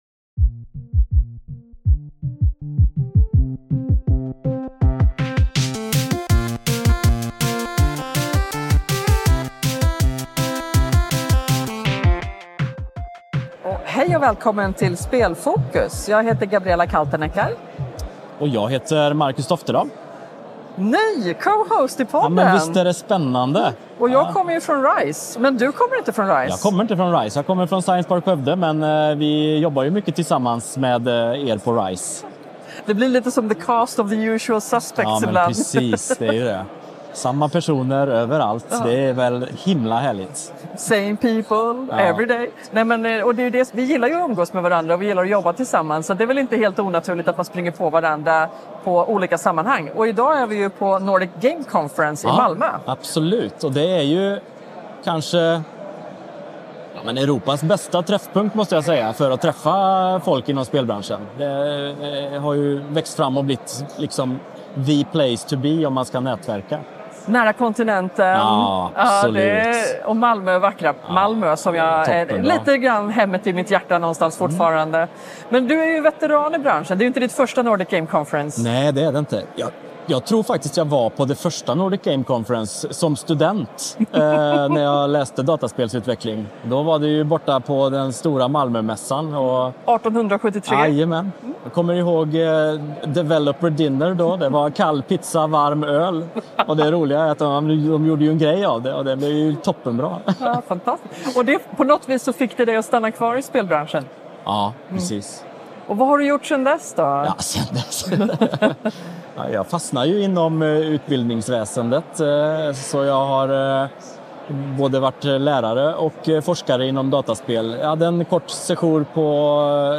S04E08 - I hjärtat av spelbranschen - ett extra långt avsnitt från Nordic Game i Malmö
I detta avsnitt får ni höra från en brokig skara deltagare, allt från spännande startups till etablerade veteraner från spelvärlden, investerare med ögonen på framtida guldkorn samt några av våra fantastiska regionala kluster, som alla utgör och bidrar till den dynamiska atmosfären i branschen. Vi ville utforska olika aktörers roller och få unika insikter i vad som driver dem.